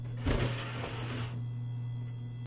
电子计算机光盘驱动器马达
描述：这是我的笔记本电脑（现在不能用了）在电脑启动时的CD驱动器马达声。